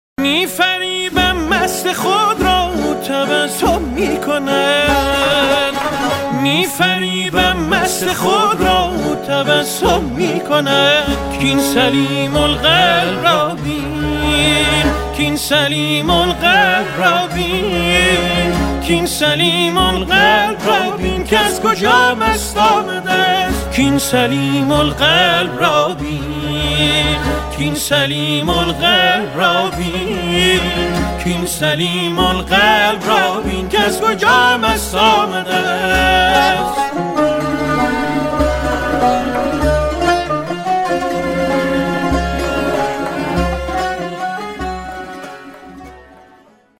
آهنگ موبایل با کلام